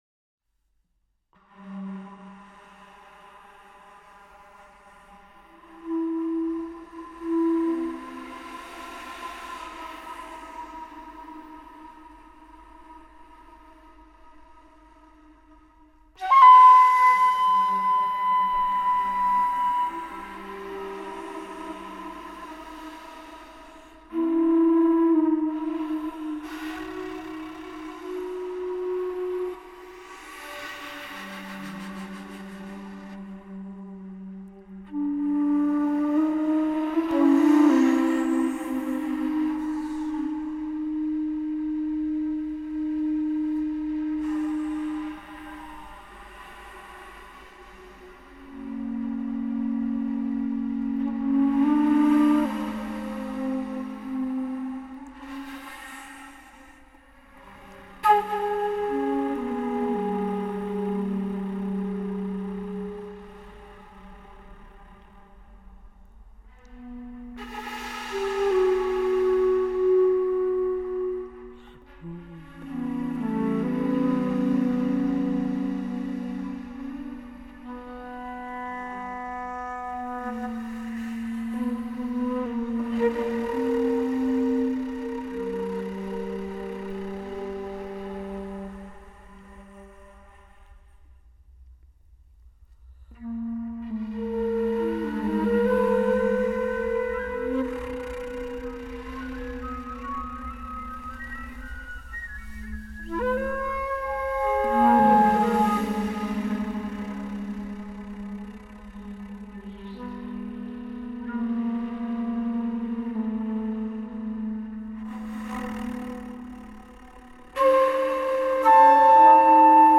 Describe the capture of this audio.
Církev Bratrska